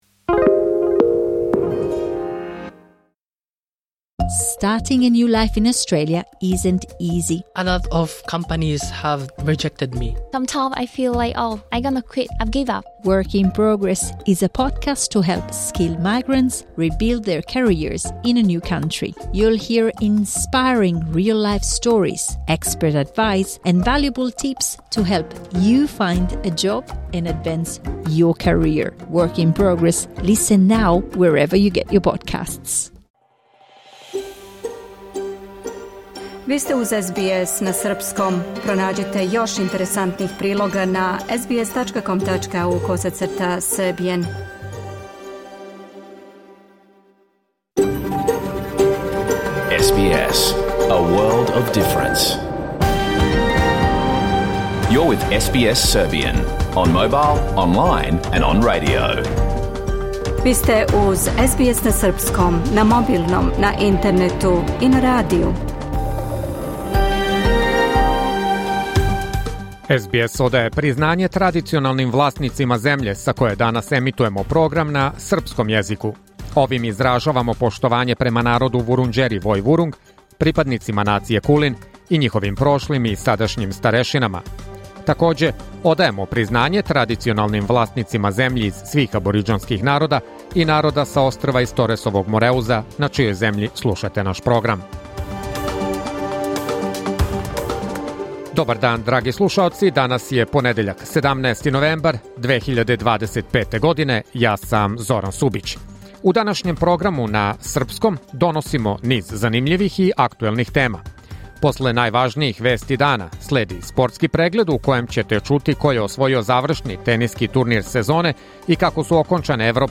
Програм емитован уживо 17. новембра 2025. године